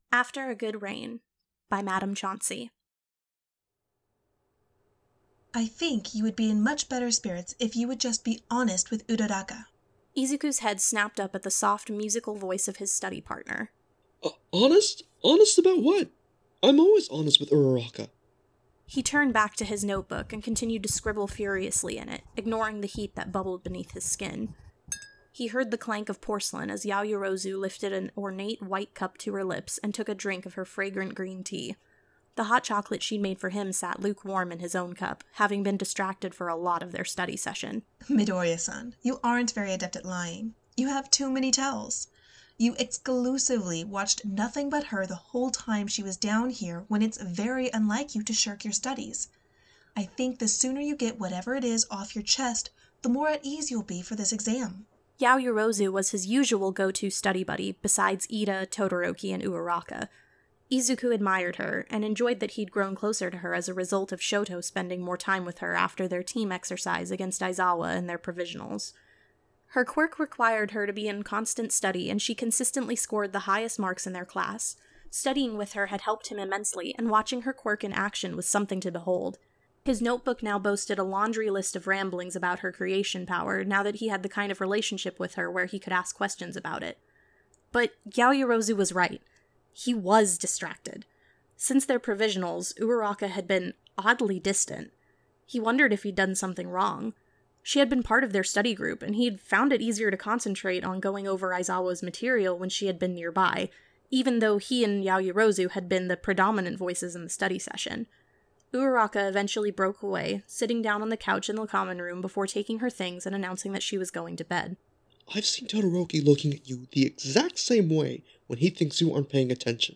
After A Good Rain | Podfic